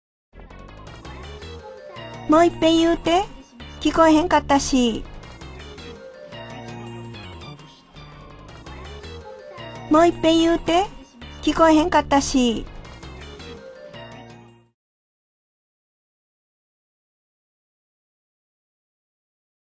• 読み上げ
市田ひろみ 語り●市田ひろみ